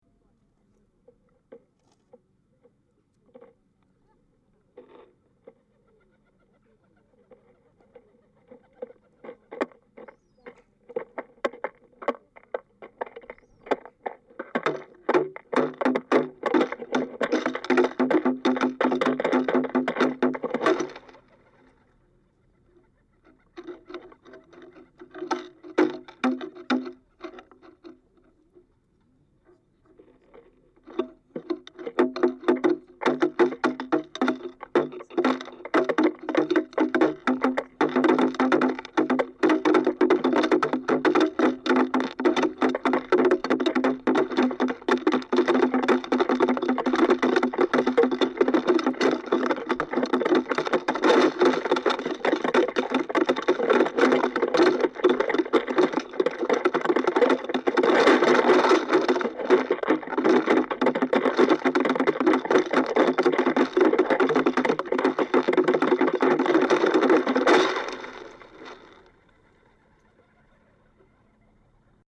The piece consisted of two silver trays filled (and re-filled) with birdseed and wildflower seed (and chips to lure seagulls), attached to contact mics and to mini amplifiers at a 5 metre distance. The birds warily approached and began pecking at the trays, generating a pulsing rhythmic noise for the 4-hour duration.
Here’s a 1m12s audio extract with pigeons feeding: